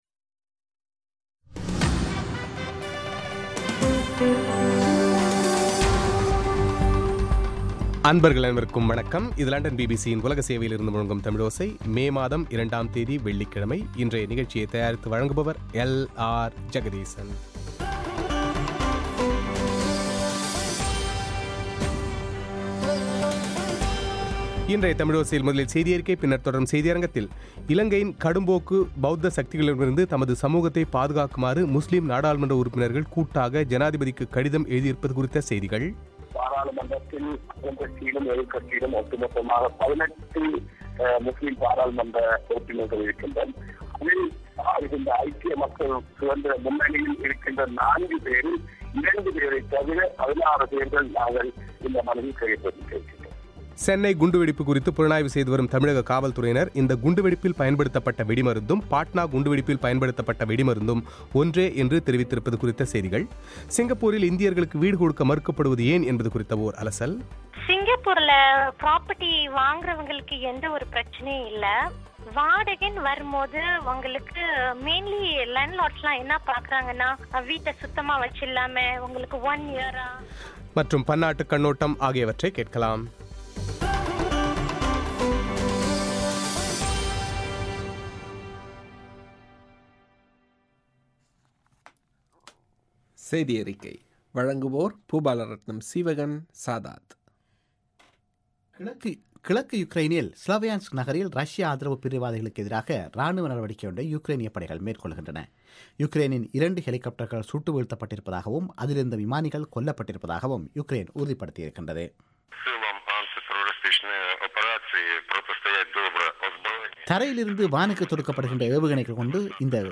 இலங்கையில் பௌத்த கடும்போக்கு சக்திகளிடமிருந்து தமது சமூகத்தைப் பாதுகாக்குமாறு முஸ்லிம் நாடாளுமன்ற உறுப்பினர்கள் கூட்டாக இலங்கை ஜனாதிபதிக்கு கடிதம் எழுதியுள்ளது குறித்து இந்த கடிதத்தில் கையெழுத்திட்டுள்ளவர்களில் ஒருவரான ஹூனைஸ் பாரூக்கின் பேட்டி;